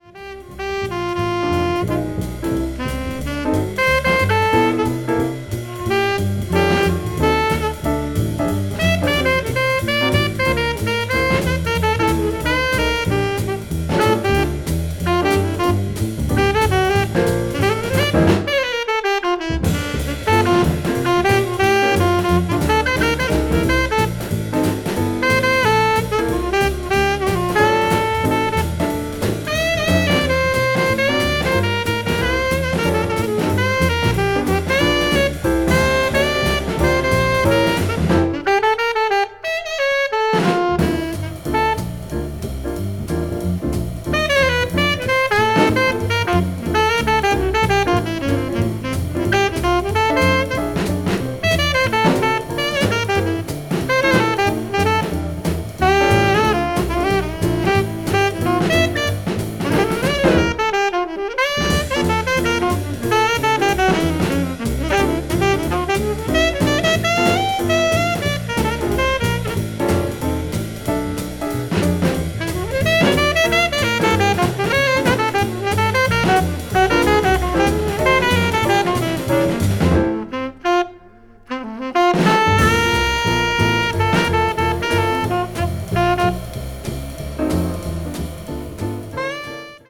media : EX/EX(わずかにチリノイズが入る箇所あり)
hard bop   modern jazz